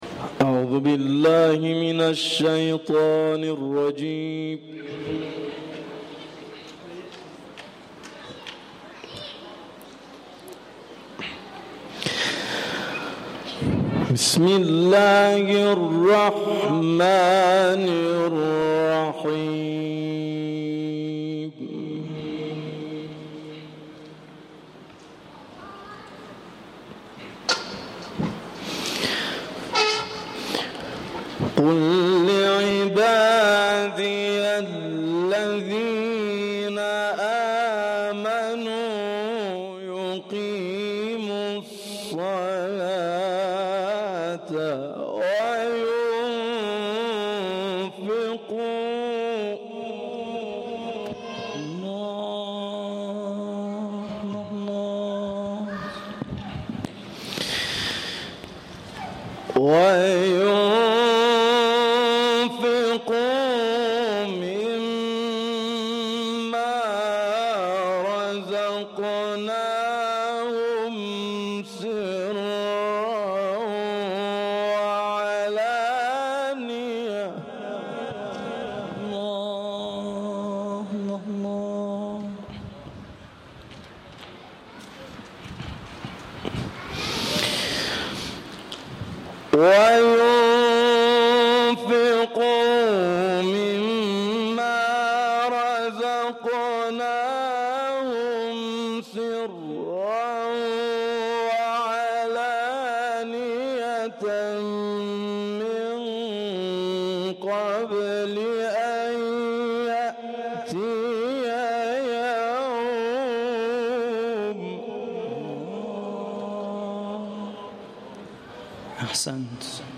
گزارش صوتی سومین کرسی تلاوت و تفسیر قرآن کریم - پایگاه اطلاع رسانی ضیافت نور